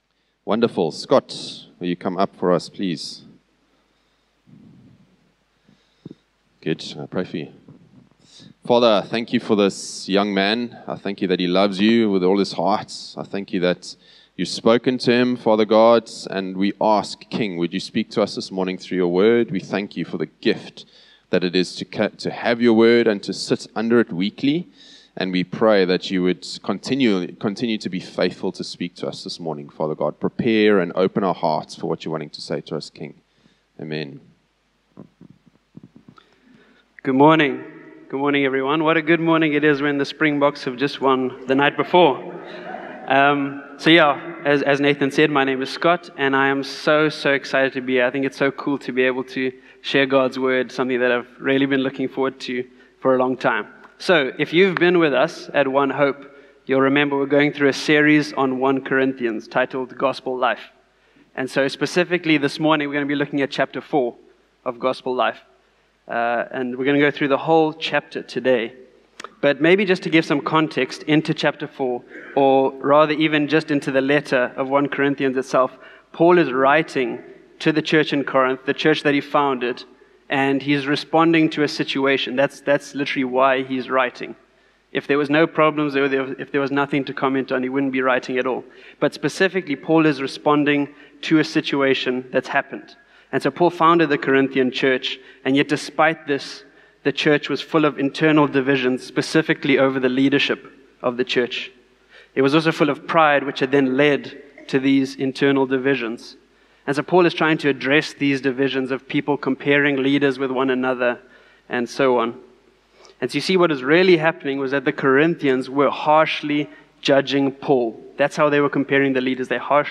A message from the series "Series Independent."